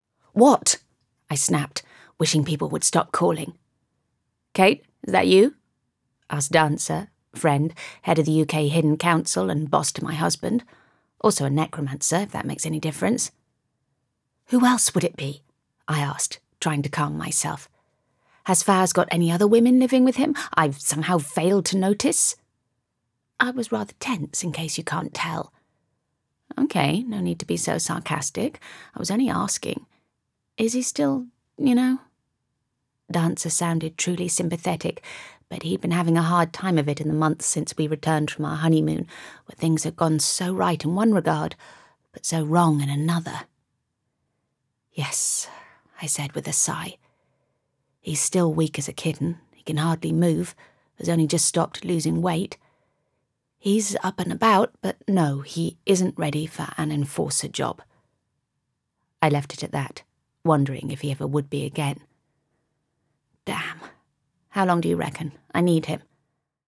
I have a naturally husky, sexy mid tone.
Native RP, Native Cornish and Fantasy